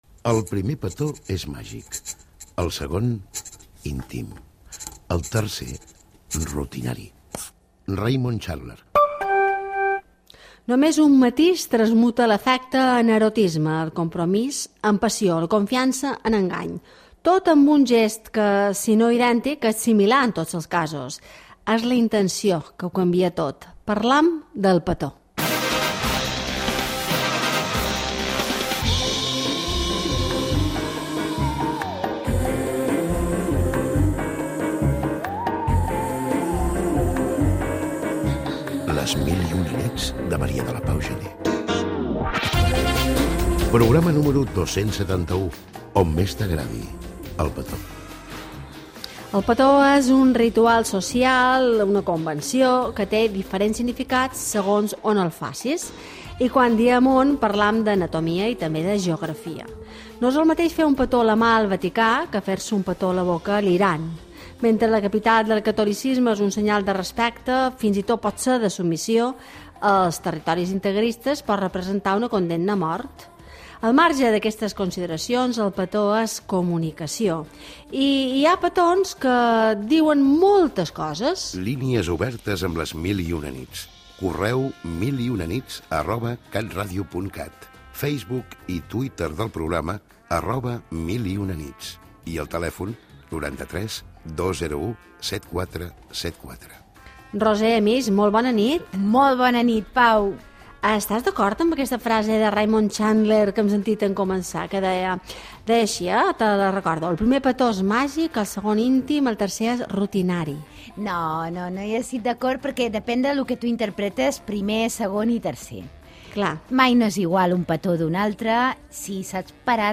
Frase inicial, careta del programa, el petó, forma de contactar amb el programa,
Divulgació